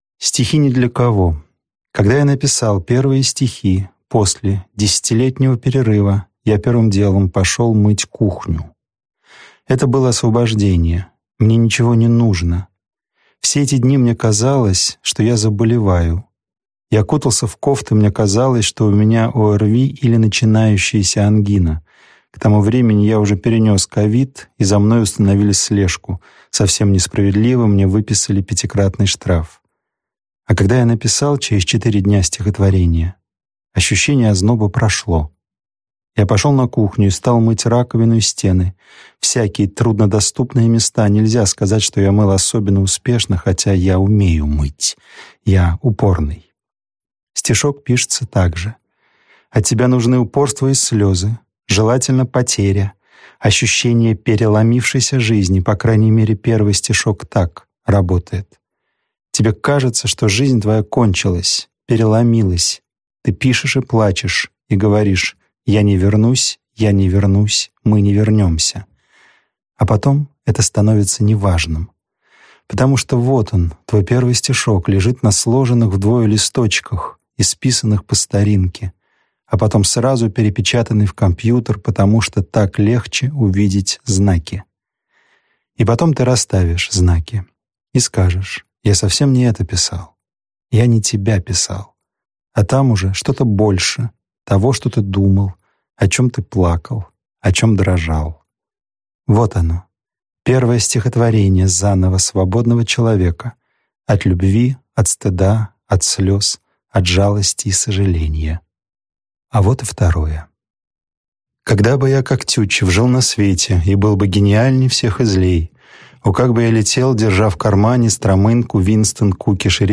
Аудиокнига Новая поэзия.